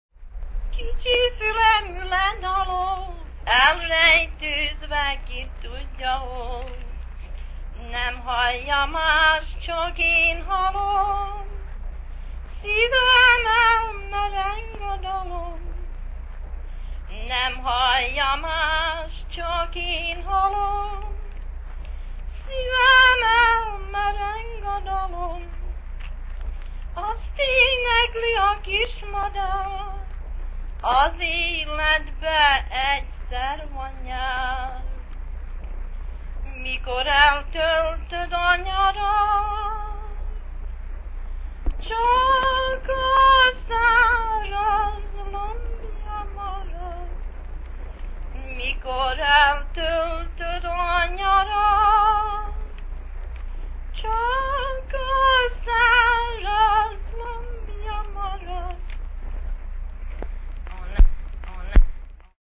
a cappella Hungarian-American folk music
in Oakland, California